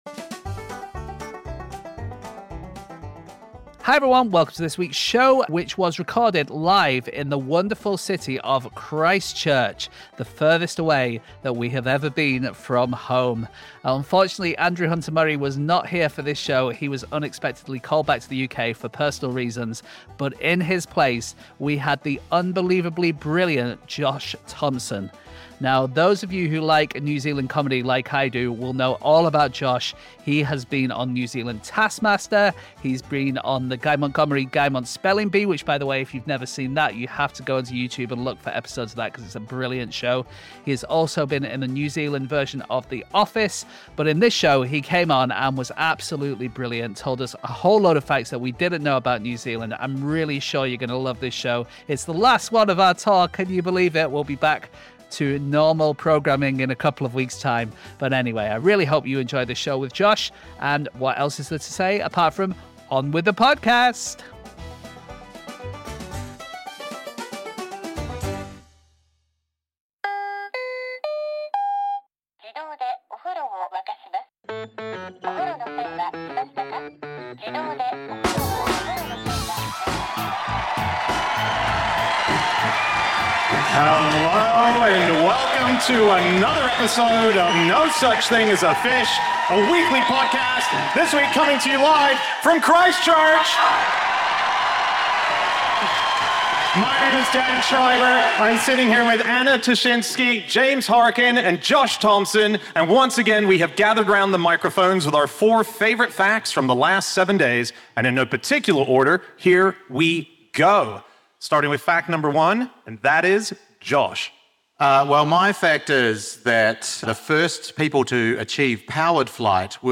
Live from Christchurch